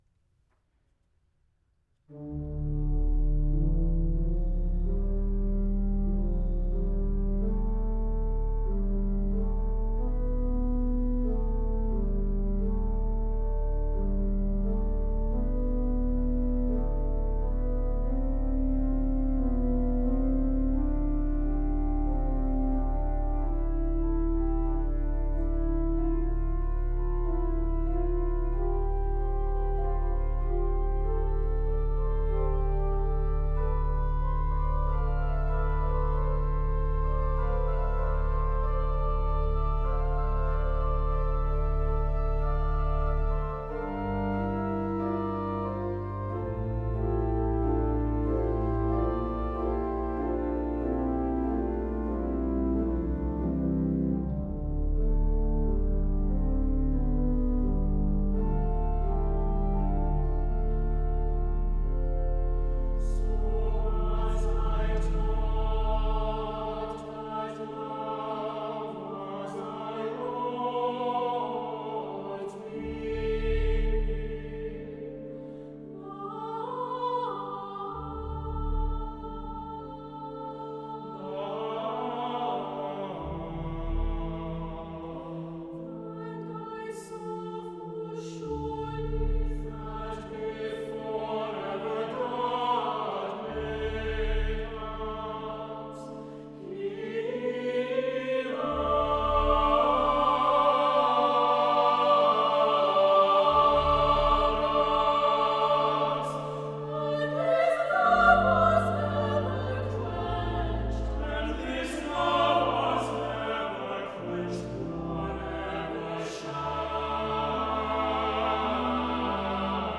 for SATB Choir and Organ (or Brass Quintet) (2001)